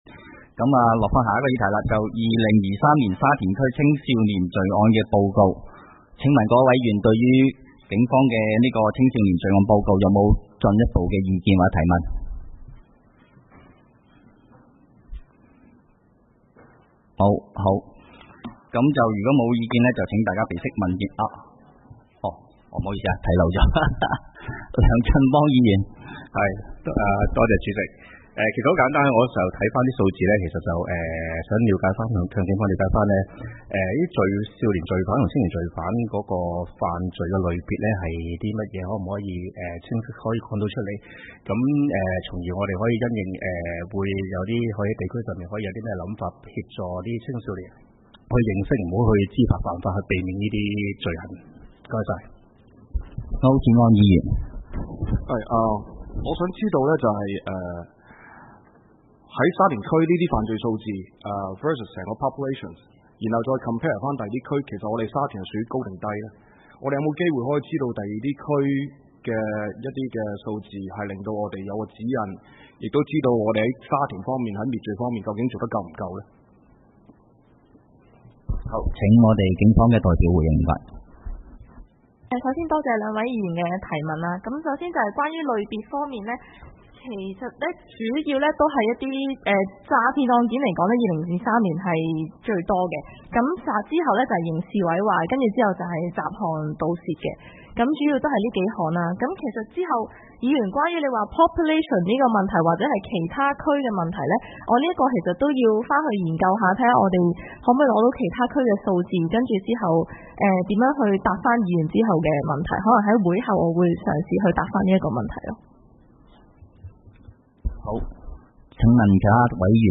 會議的錄音記錄
地點: 沙田民政事務處441會議室